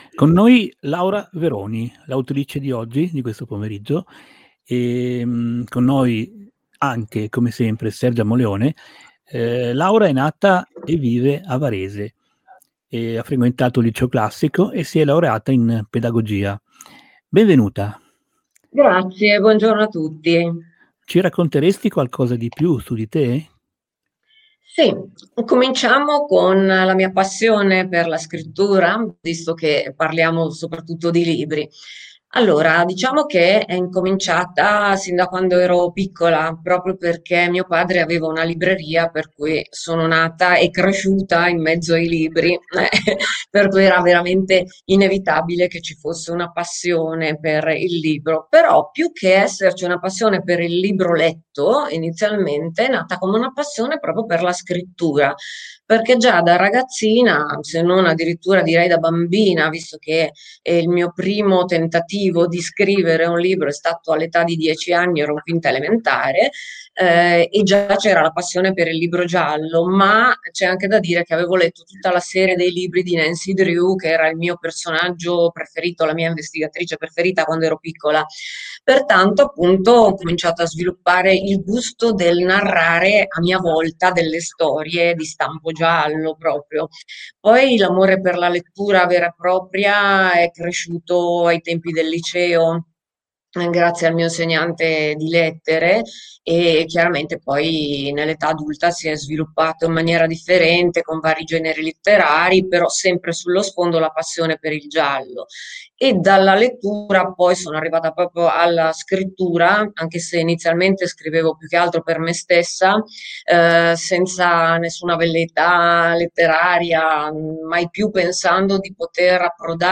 Per ascoltare l'intervista CLICCARE QUI Si ringraziano RBG Radio